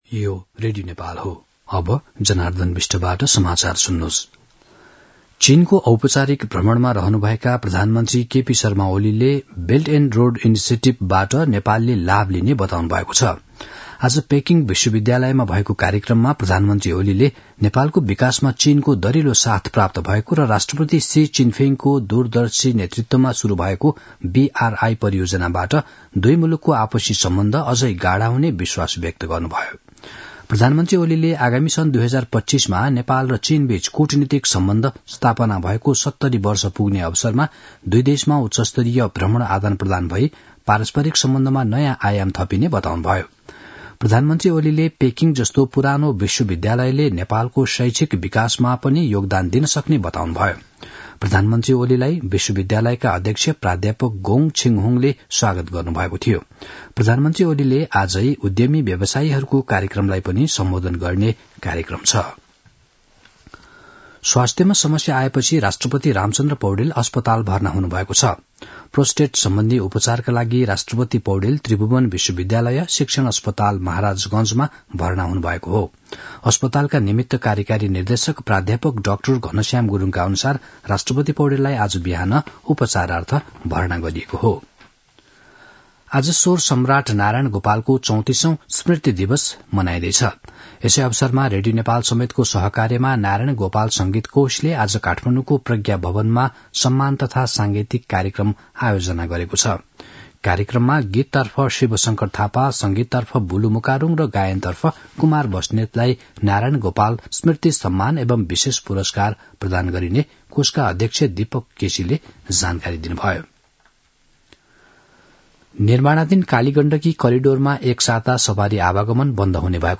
दिउँसो १ बजेको नेपाली समाचार : २० मंसिर , २०८१
1-pm-nepali-news-1-3.mp3